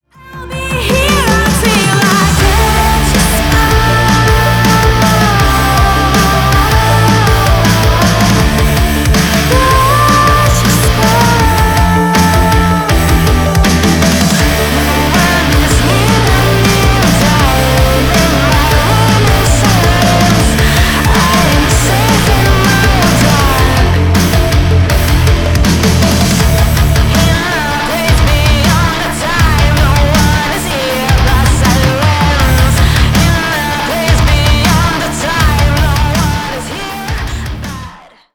Рок Металл # громкие